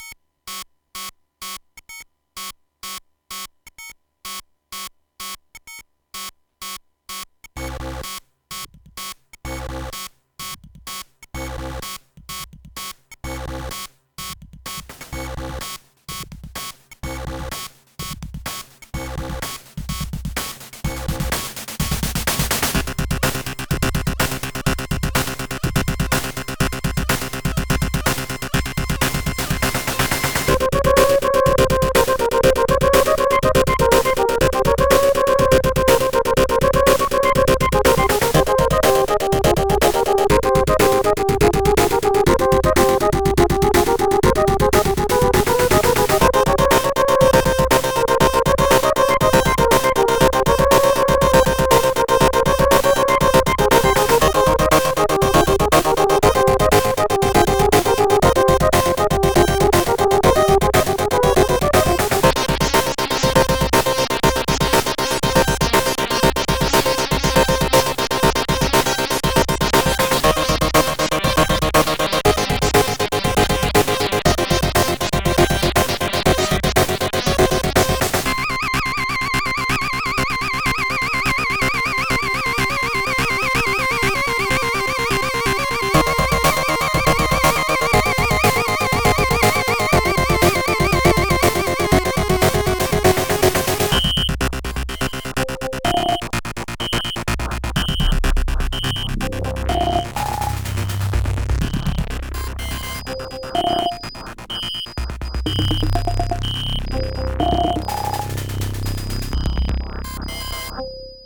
Intro music OPL2 vs.